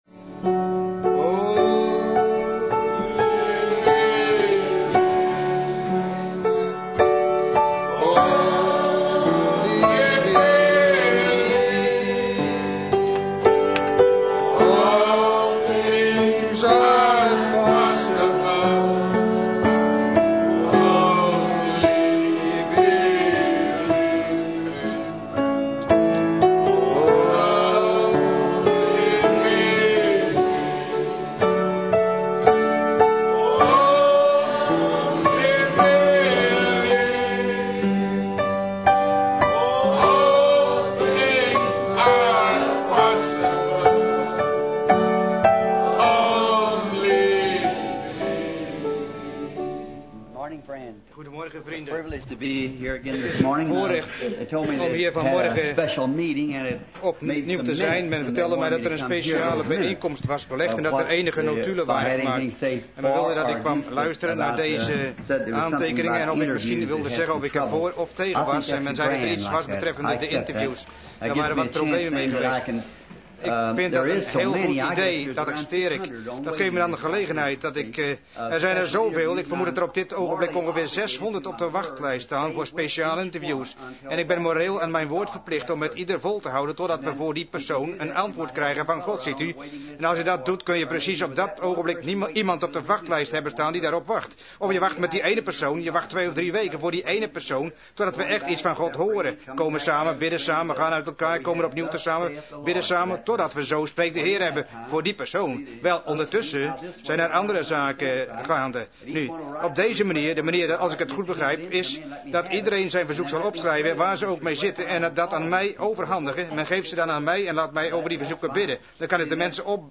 Vertaalde prediking "Paradox" door William Marrion Branham te Branham Tabernacle, Jeffersonville, Indiana, USA, 's ochtends op zondag 10 december 1961